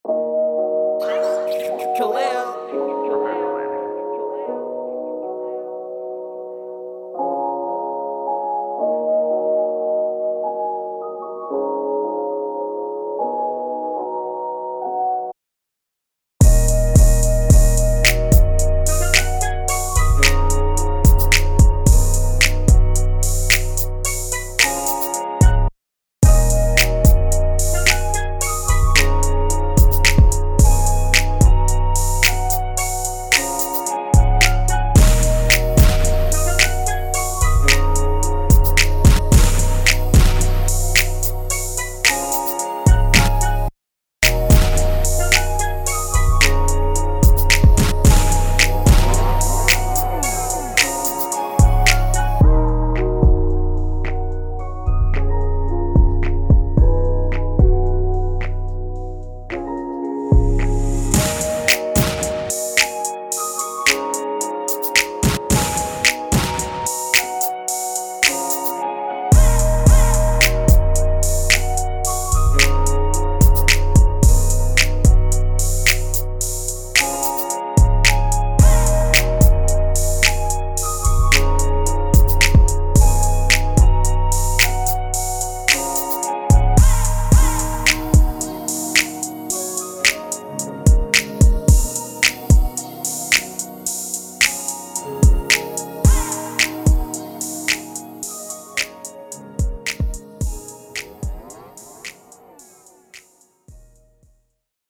This is the official instrumental
Hip-Hop Instrumentals